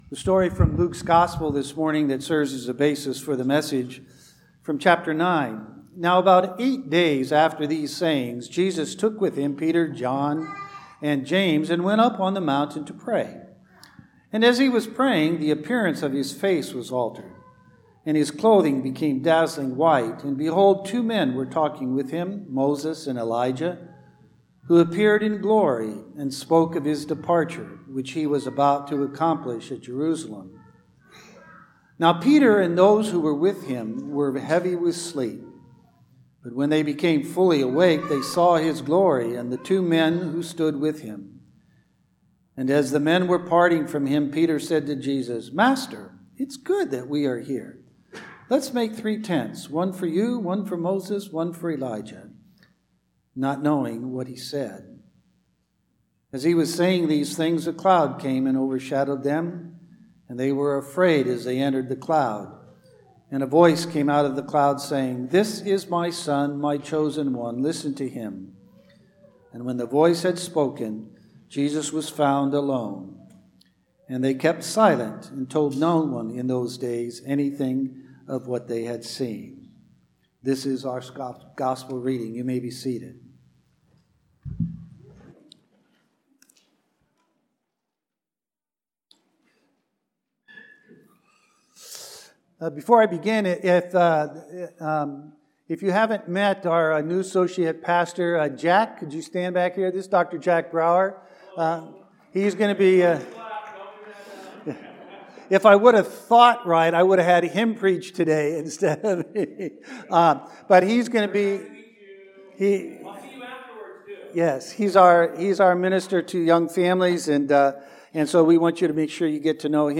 Sermon-3-3-2019.mp3